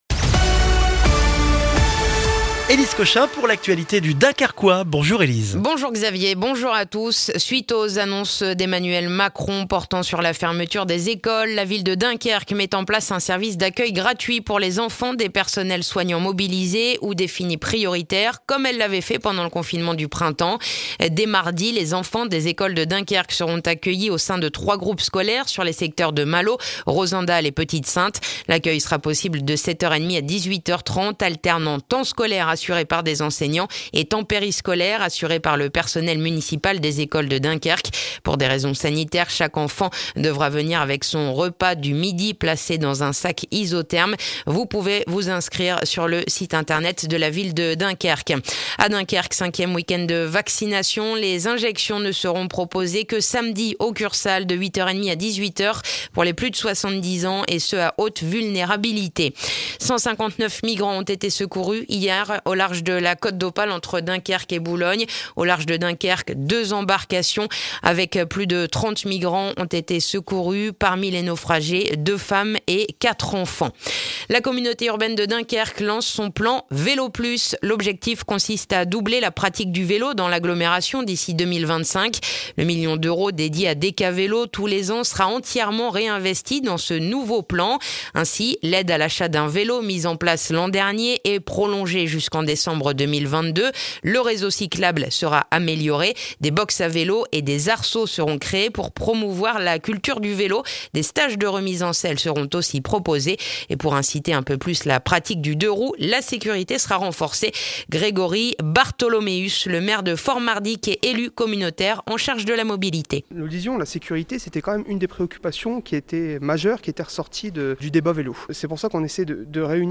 Le journal du vendredi 2 avril dans le dunkerquois